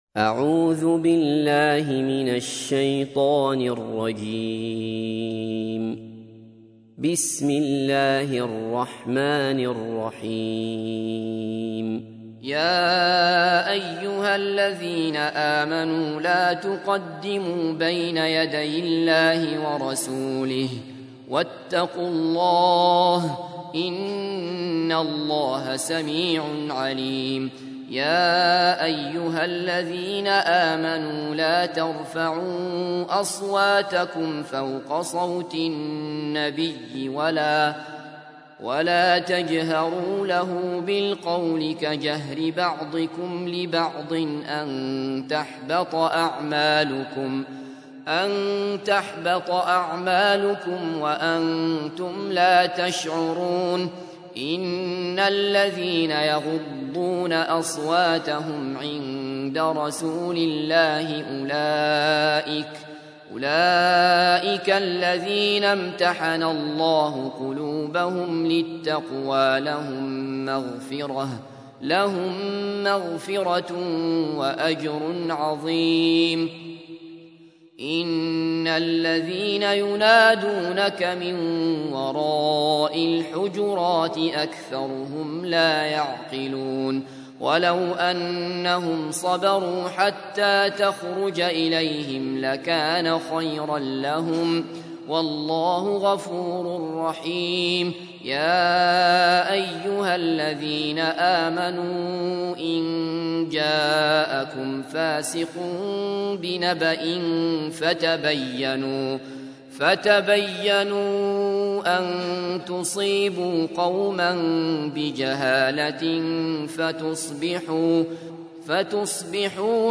تحميل : 49. سورة الحجرات / القارئ عبد الله بصفر / القرآن الكريم / موقع يا حسين